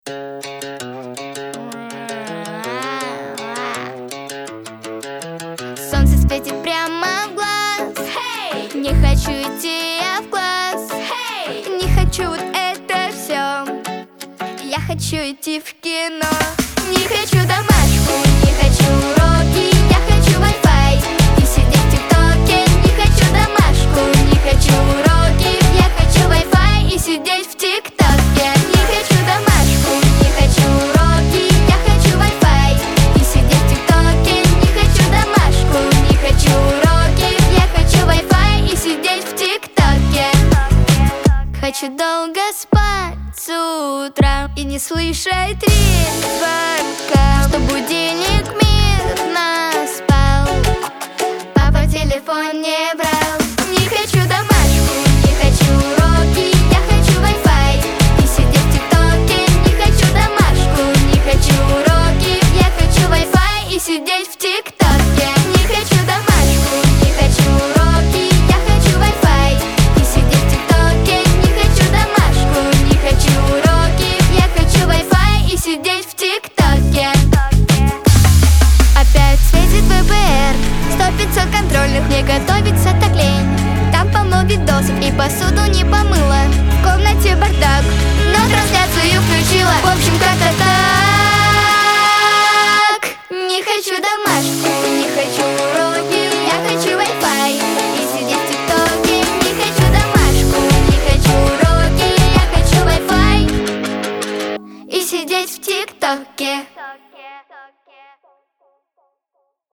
Лирика , дуэт